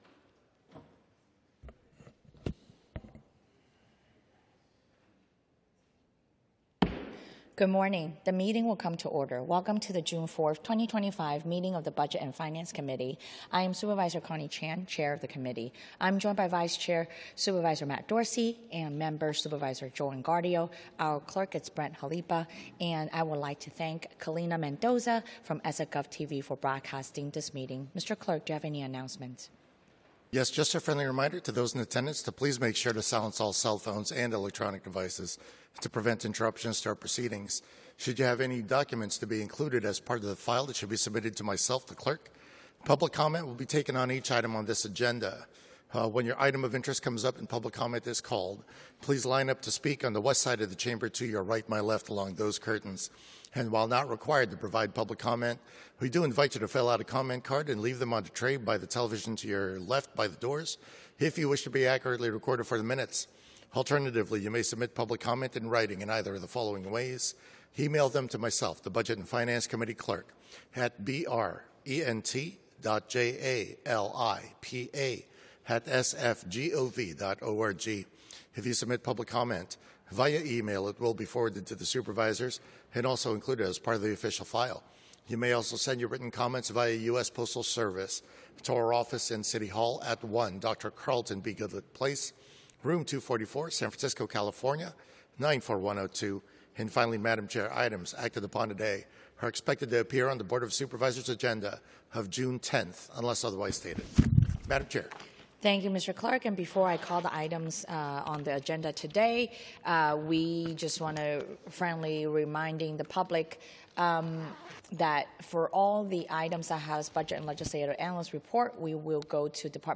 BOS Budget and Finance Committee - Regular Meeting - Jun 04, 2025